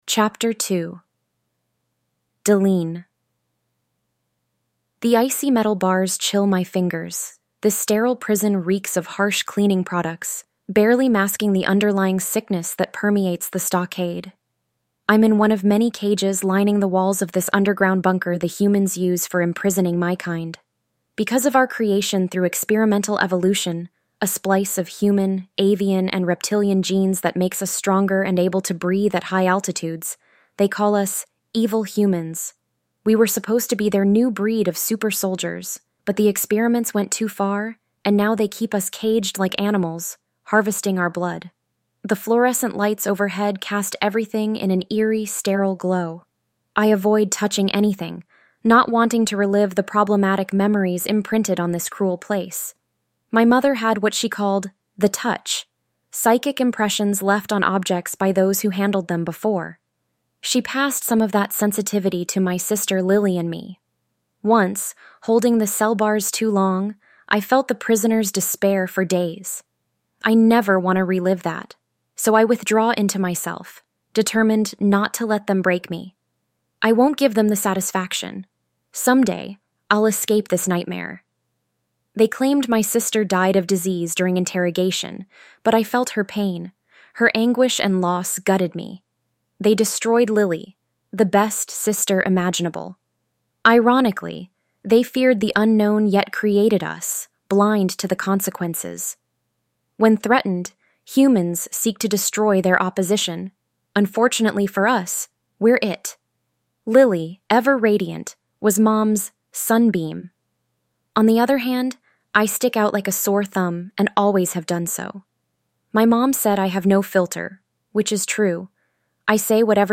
It features a full voice cast, bringing the story to life in a completely new and immersive way.